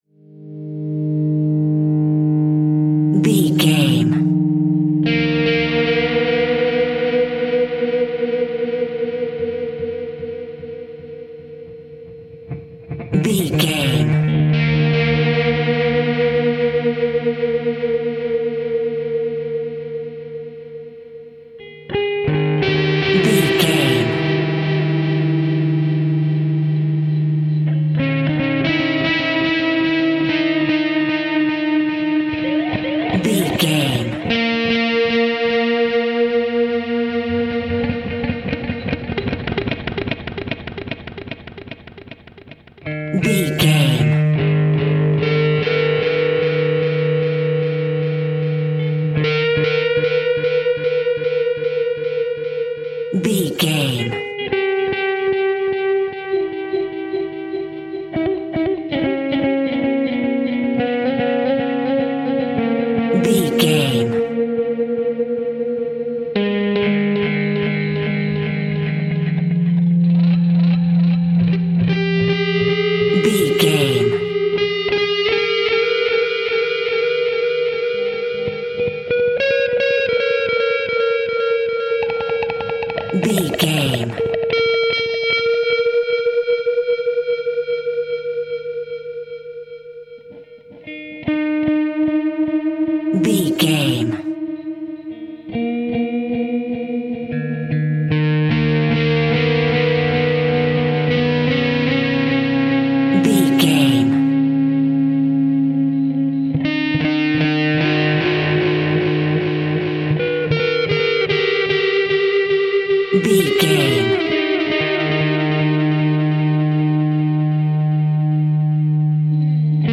Death Metal Atmosphere.
Aeolian/Minor
G#
Slow
scary
ominous
dark
haunting
eerie
electric guitar
synth
pads